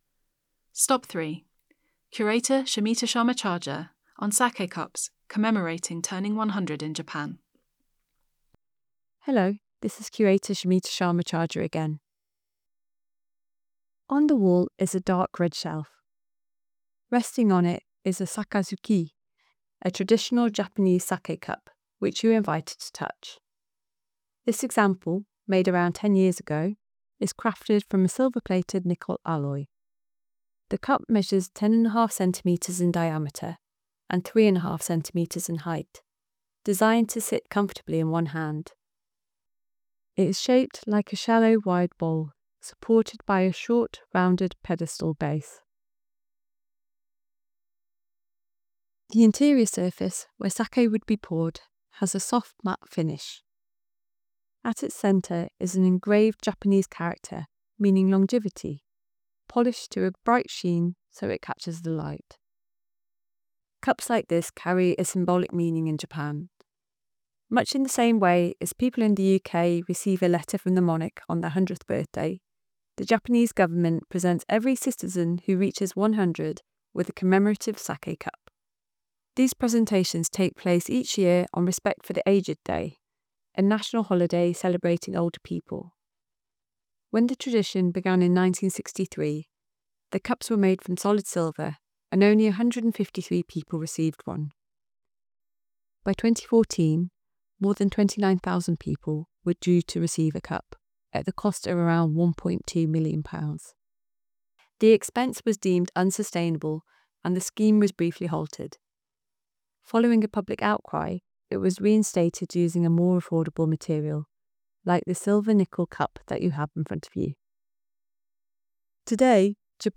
Explore our exhibitions using your own device, with audio description, British Sign Language and gallery captions